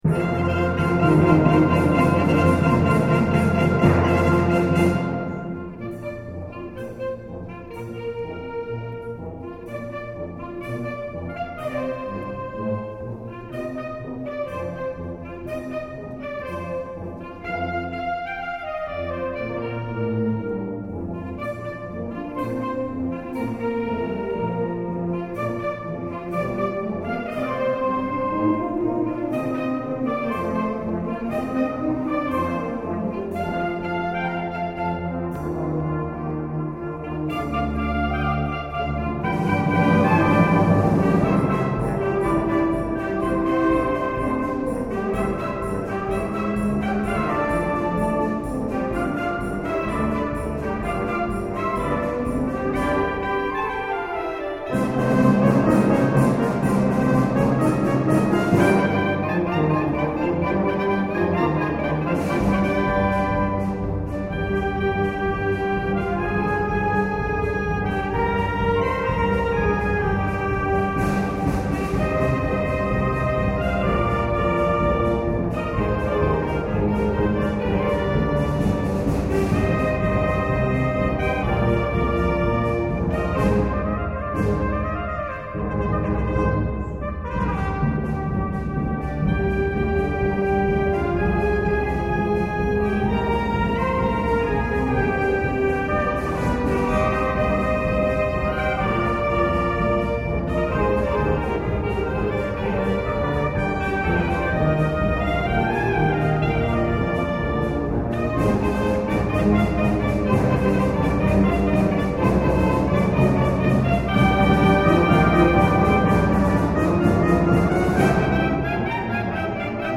BANDA MUSICALE
Concerto di Natale 2010